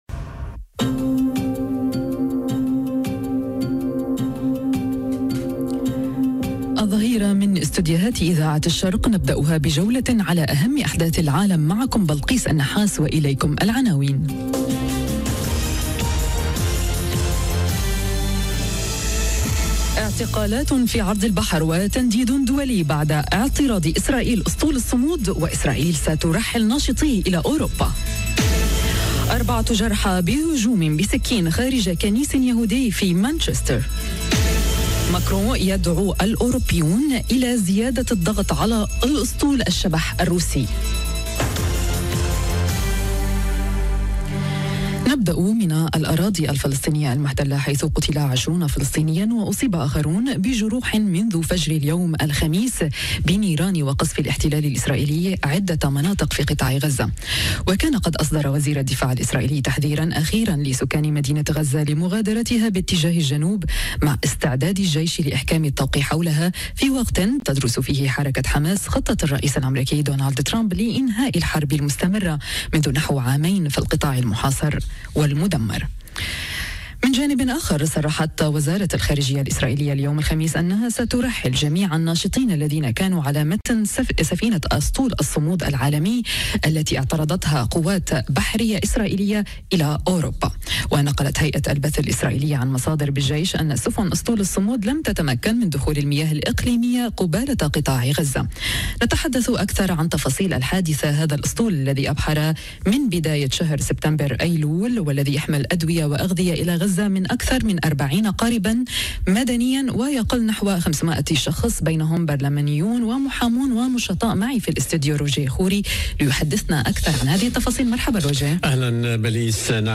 نشرة أخبار الظهيرة:اعتقالات في عرض البحر وتنديد دولي بعد اعتراض إسرائيل "أسطول الصمود" - Radio ORIENT، إذاعة الشرق من باريس